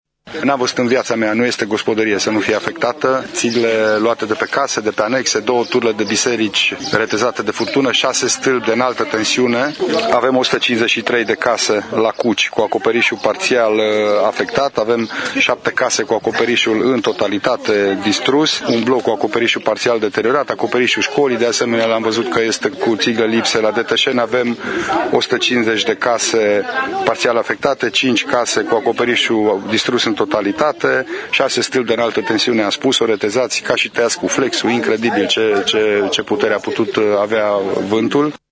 Prefectul Lucian Goga a declarat pentru Radio Tg.Mureș că nu a văzut în viața sa așa ceva.
Prefect-Goga.mp3